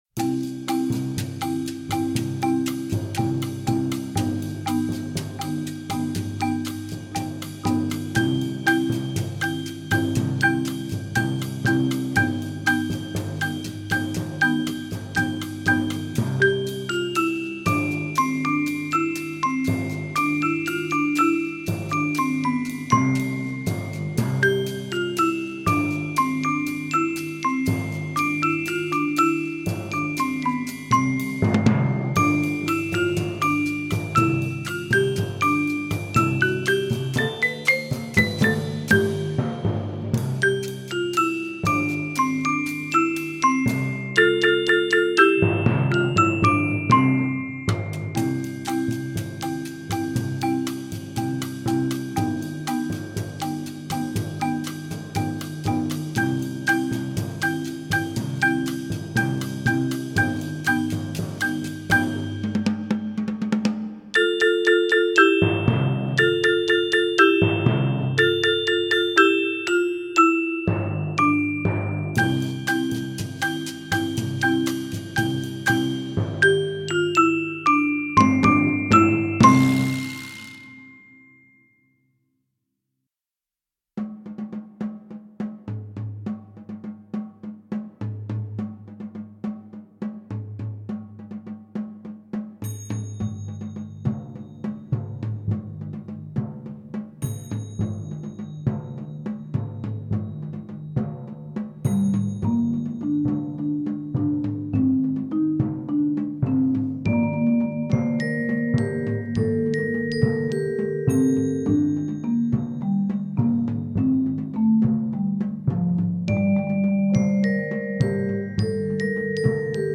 Voicing: 5-7 Percussion